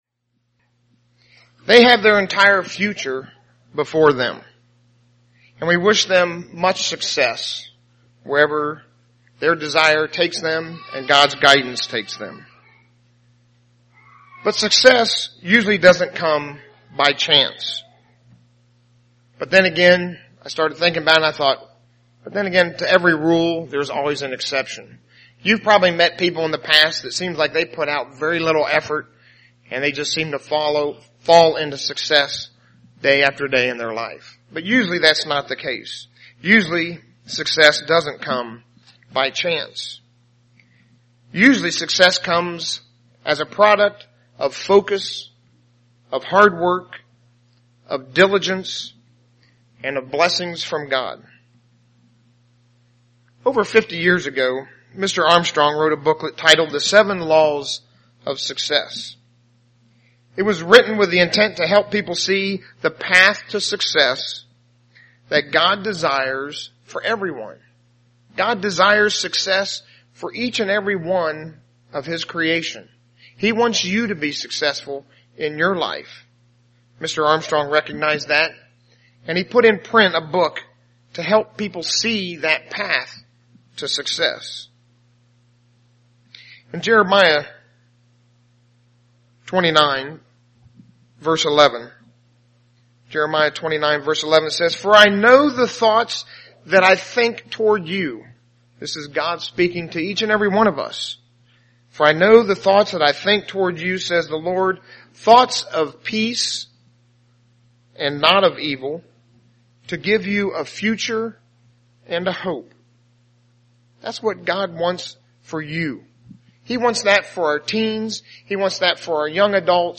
UCG Sermon Notes Notes: Success doesn't happen by chance, it comes by hard work. 1.)